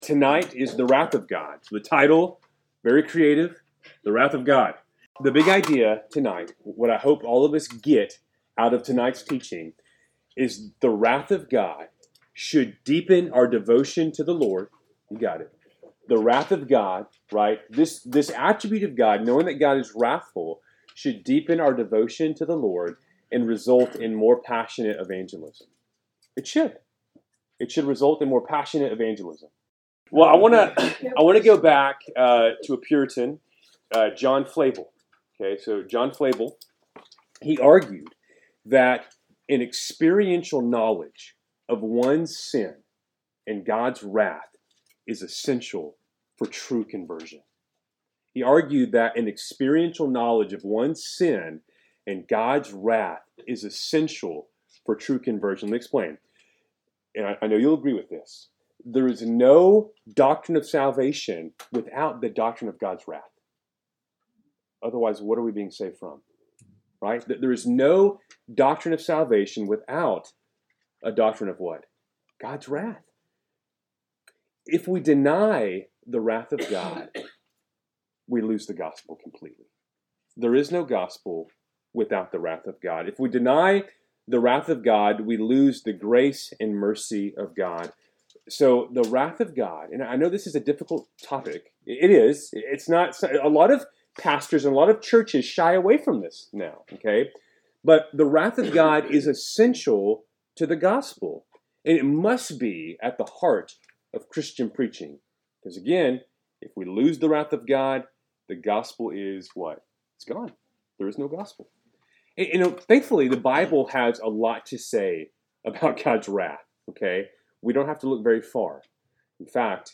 4-9-25-Wednesday-Night-Bible-Study.mp3